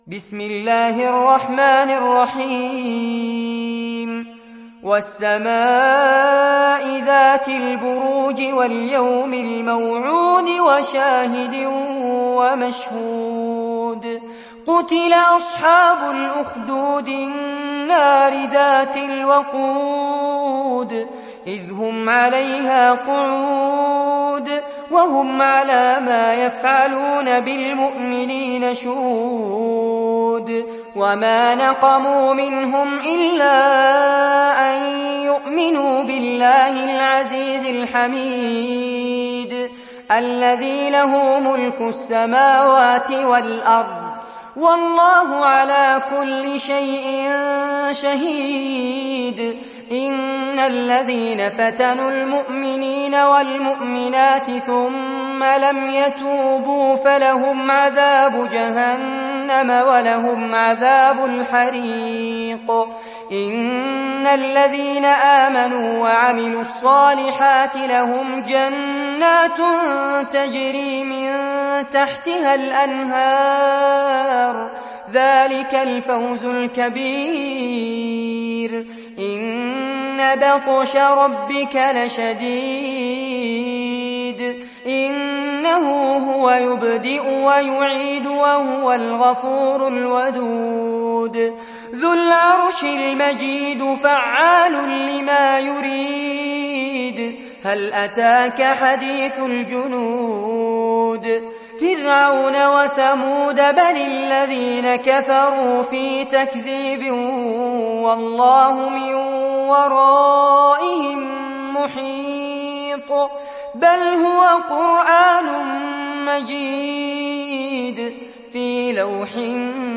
دانلود سوره البروج mp3 فارس عباد روایت حفص از عاصم, قرآن را دانلود کنید و گوش کن mp3 ، لینک مستقیم کامل